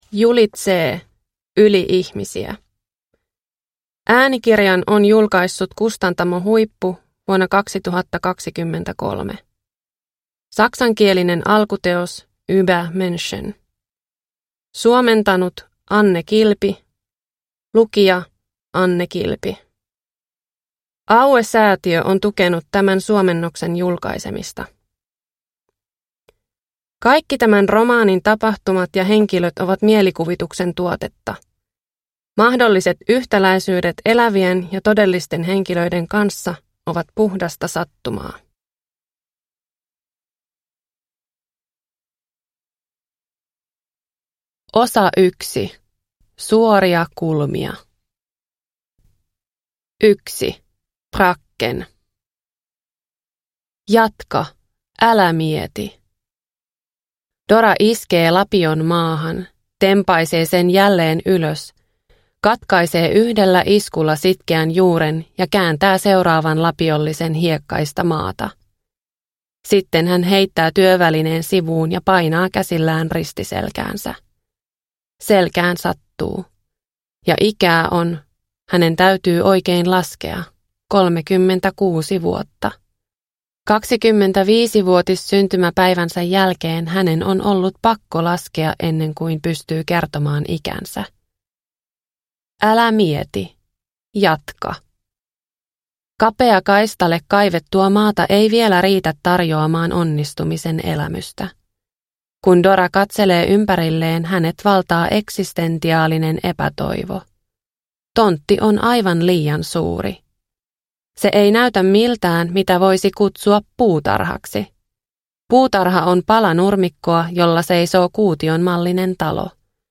Yli-ihmisiä – Ljudbok